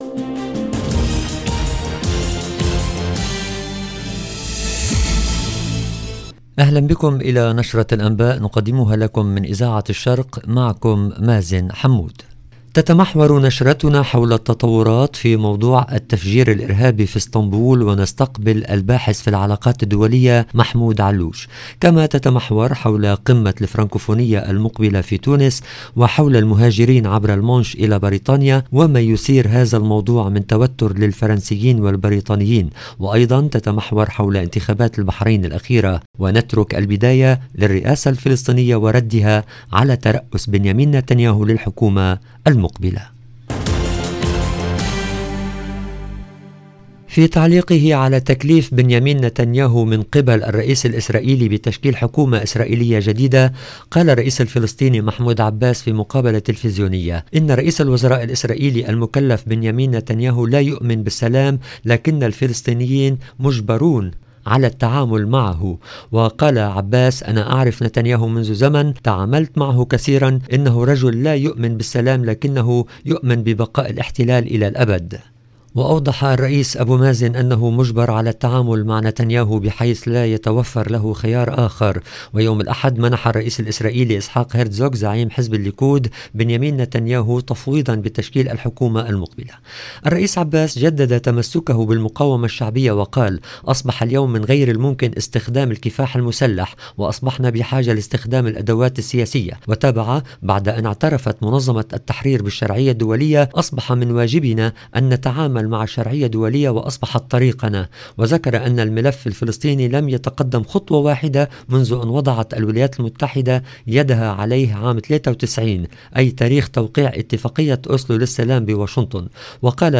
LB JOURNAL EN LANGUE ARABE